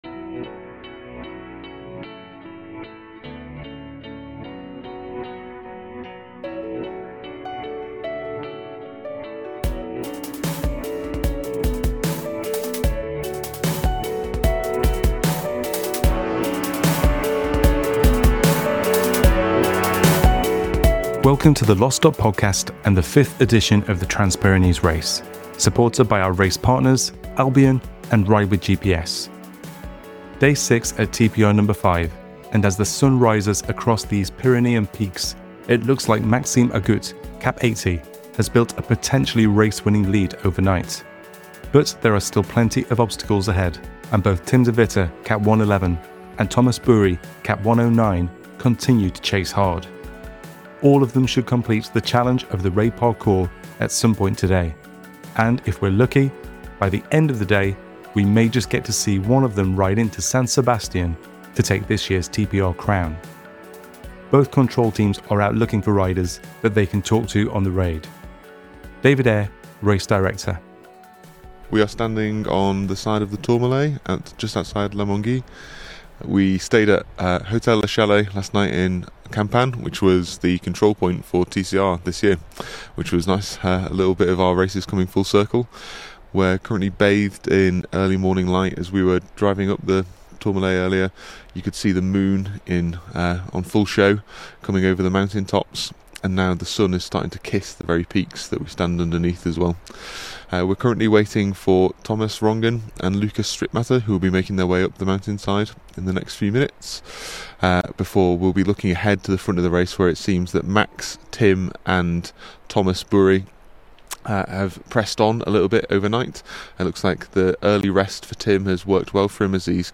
As the kilometres grind on, some riders are weary, while others are jubilant in their experience, not wanting their time here to end. Hear more from the riders on the Raid in our latest podcast episode from Day 6.